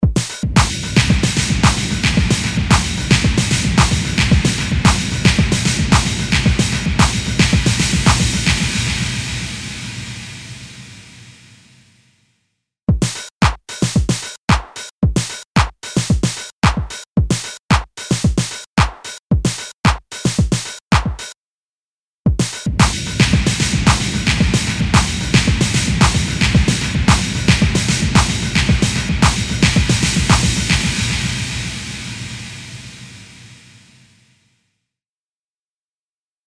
Massive Otherworldly Reverb
Blackhole | Drum Loop | Preset: Stairway Delay
Blackhole-Eventide-Techno-Drum-Loop-Stairway-Delay.mp3